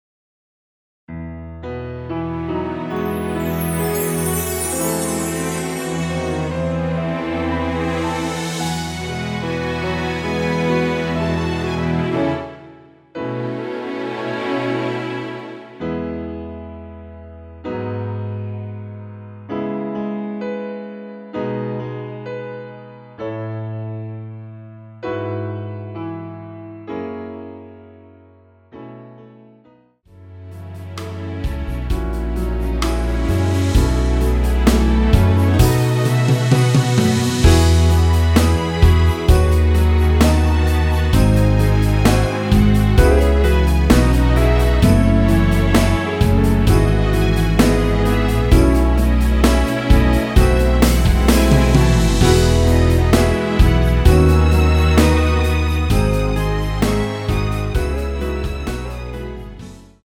MR입니다.
앞부분30초, 뒷부분30초씩 편집해서 올려 드리고 있습니다.